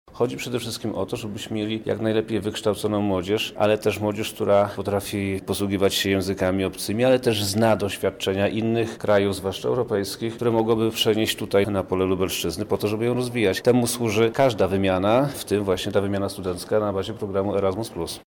– mówi wojewoda lubelski